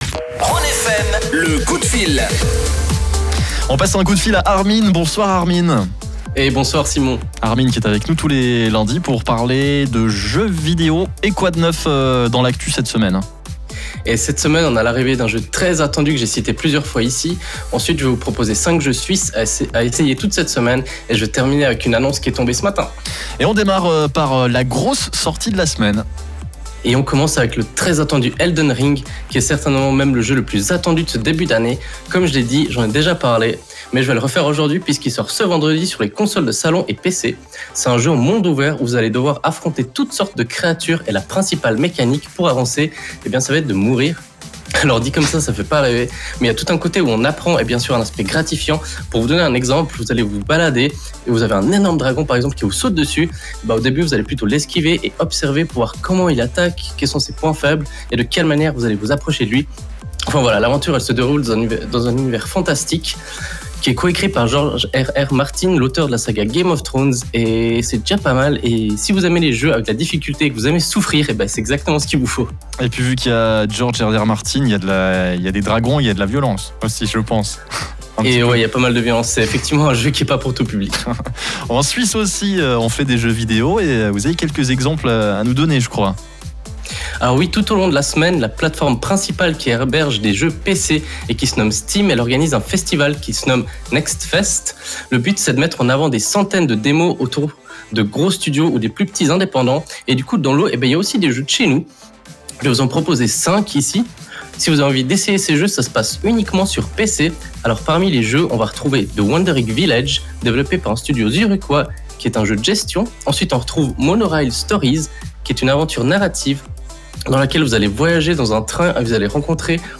C’est lundi, et comme chaque début de semaine, on parle de l’actualité dans notre petite chronique gaming sur la radio Rhône FM. Aujourd’hui je vous parle d’Elden Ring, de cinq jeux suisses à découvrir et de l’annonce du jour signée Capcom. Avant de vous laisser profiter de ce direct, je m’excuse pour le désert qui semble traverser ma gorge durant ces quelques minutes.